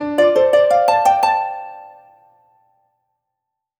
collectable_item_bonus_01.wav